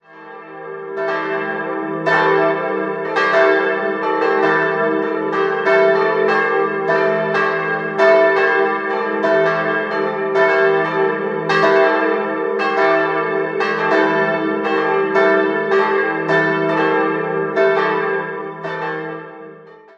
Aus dem Turm erklingen drei Euphonglocken. 3-stimmiges TeDeum-Geläute: e'-g'-a'